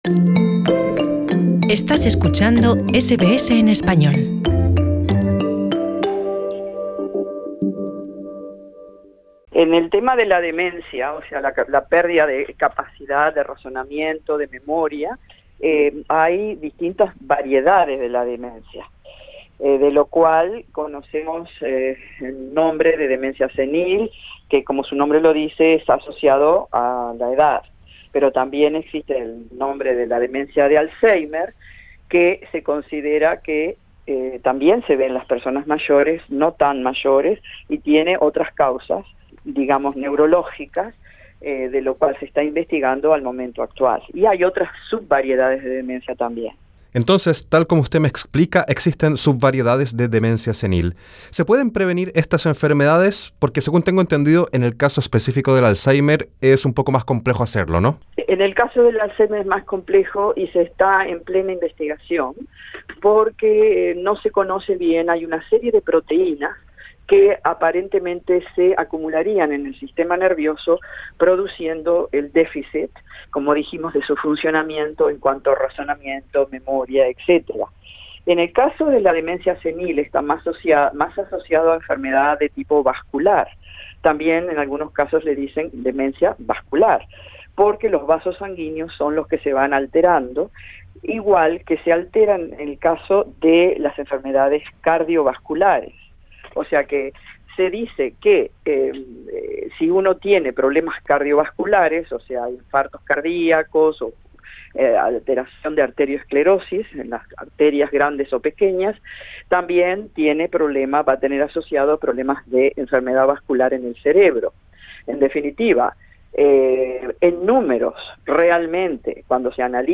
En Radio SBS entrevistamos a la doctora